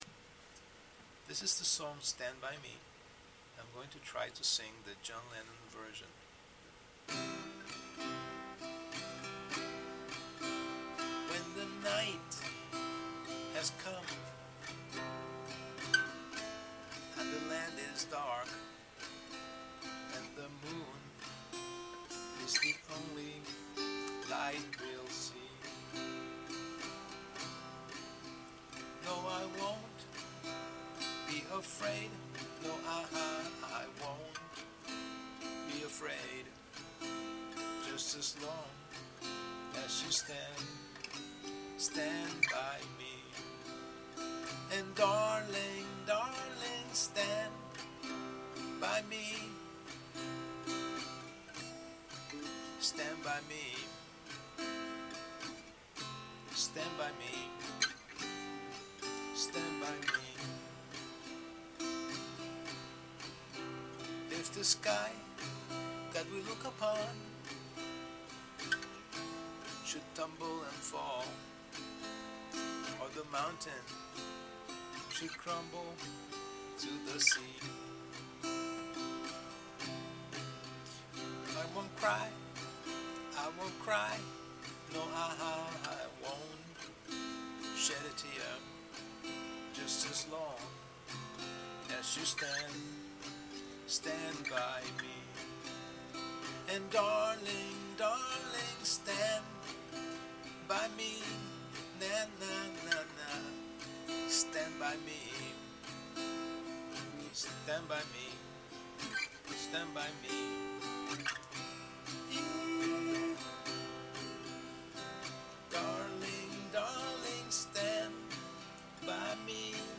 Guitar practice Stand By Me